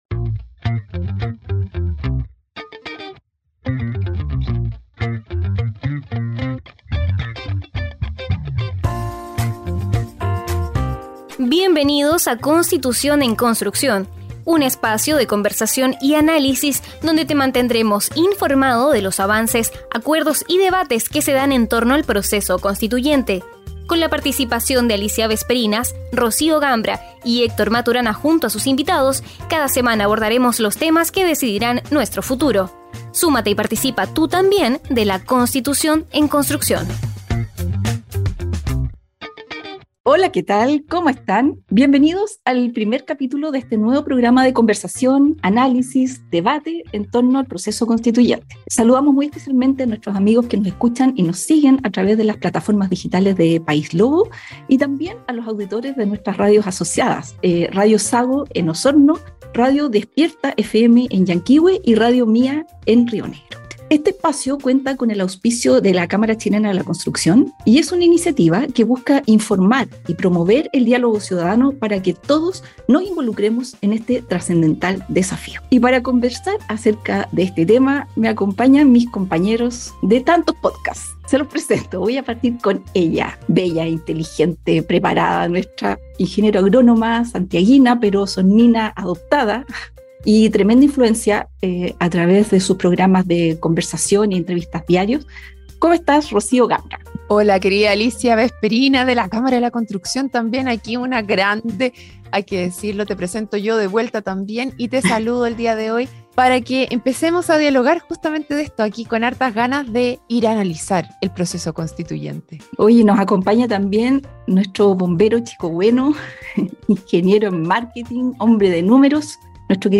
Además, contarás con la participación de nuestros destacados panelistas